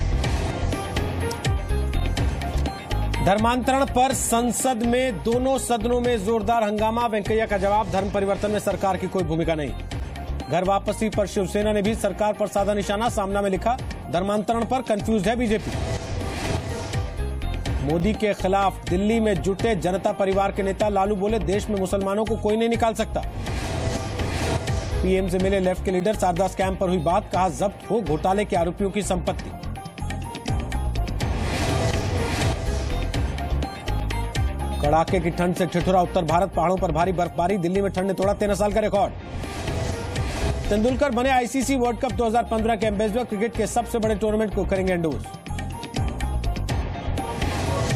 Top news headlines at 5 pm